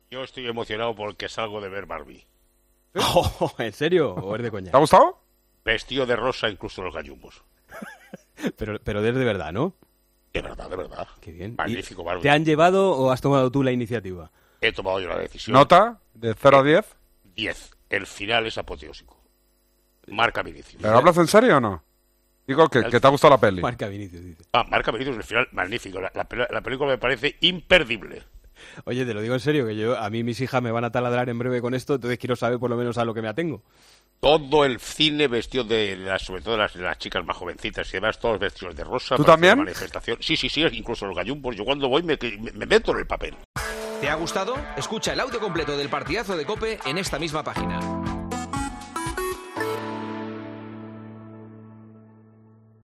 El tertuliano de El Partidazo de COPE habla sobre una de las películas del verano y se posiciona sobre la nota que le daría, justo después de verla: "Marca Vinicius".